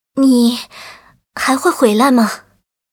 文件 文件历史 文件用途 全域文件用途 Erze_fw_06.ogg （Ogg Vorbis声音文件，长度2.9秒，86 kbps，文件大小：31 KB） 源地址:地下城与勇士游戏语音 文件历史 点击某个日期/时间查看对应时刻的文件。